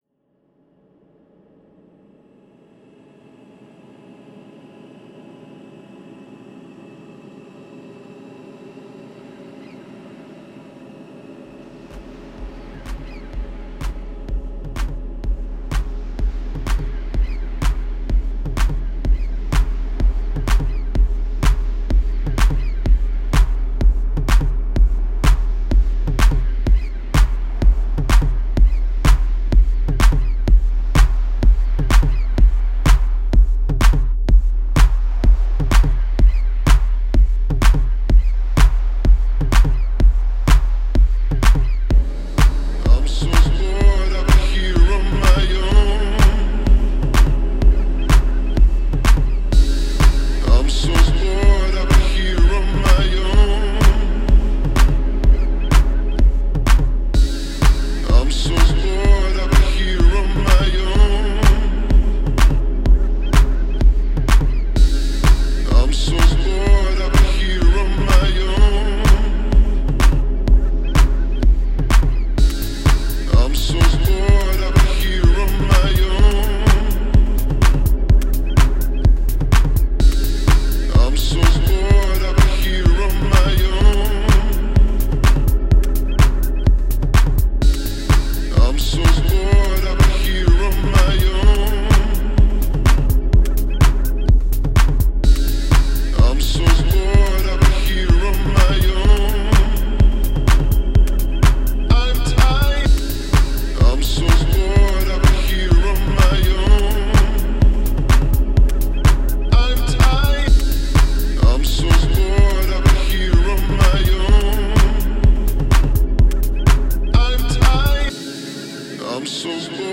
Post-House and piano riffs with dark stretched out sampling.